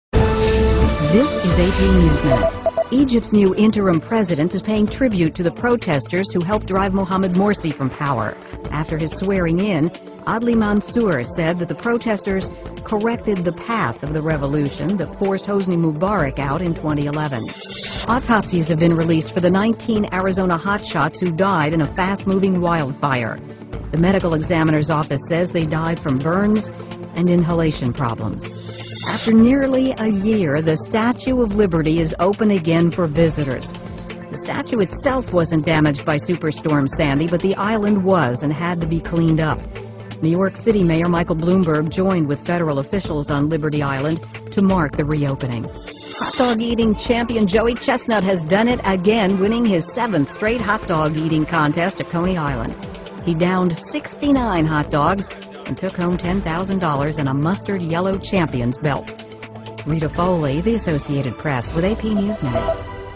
在线英语听力室美联社新闻一分钟 AP 2013-07-09的听力文件下载,美联社新闻一分钟2013,英语听力,英语新闻,英语MP3 由美联社编辑的一分钟国际电视新闻，报道每天发生的重大国际事件。电视新闻片长一分钟，一般包括五个小段，简明扼要，语言规范，便于大家快速了解世界大事。